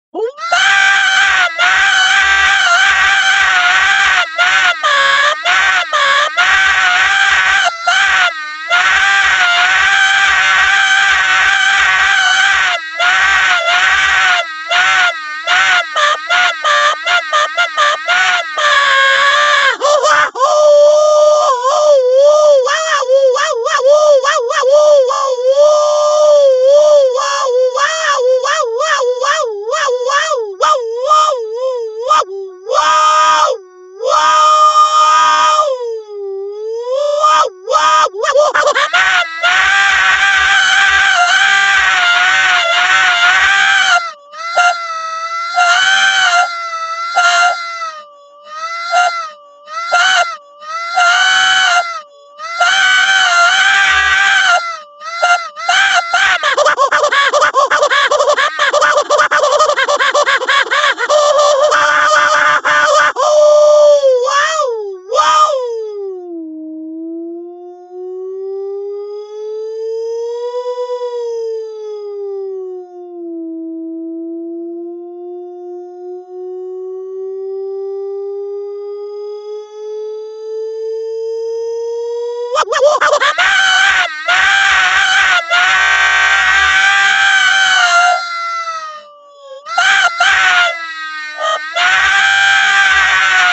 MARIO SCREAMING-(p).m4a